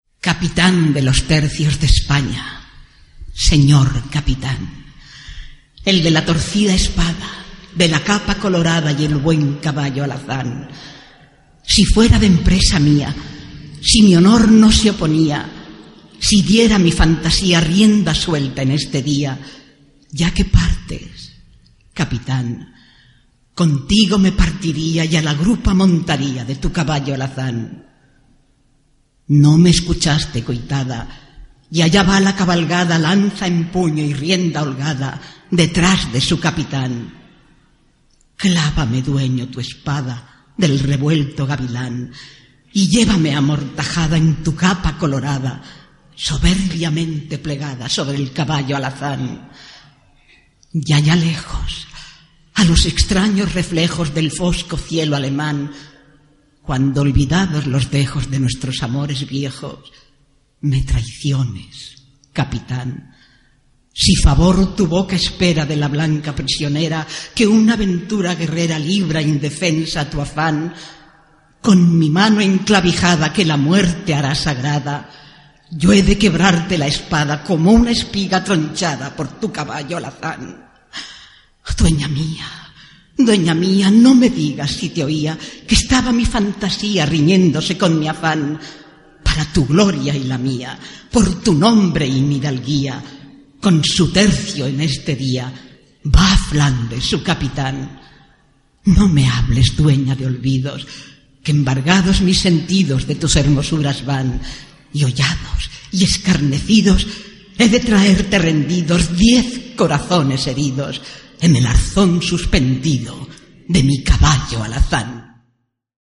Més endavant, i en la seva condició de col·laboradora, forma part del grup d’actors de ‘Teatro invisible’ de RNE a Barcelona, un programa dramàtic radiofònic que es va començar a emetre el 1949 i que va durar vint anys en antena.